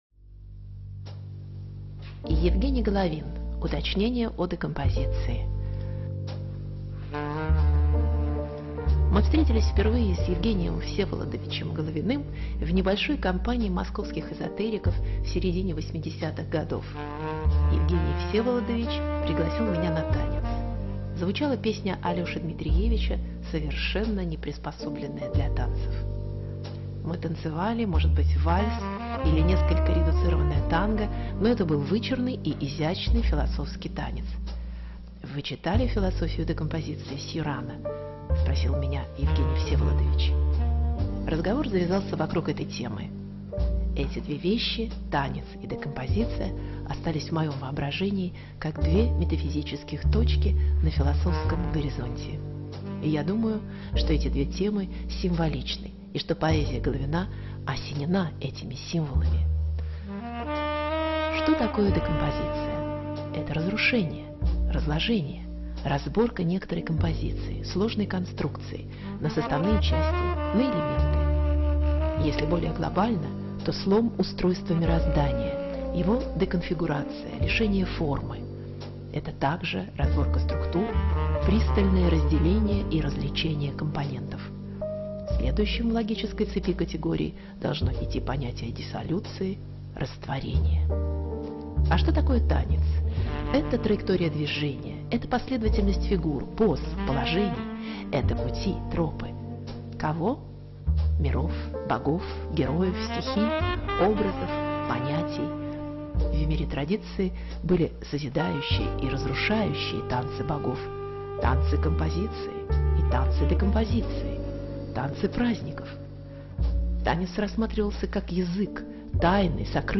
Юрий Мамлеев о Евгении Головине. Вечер памяти мэтра - 40 дней со дня смерти. 2010 год.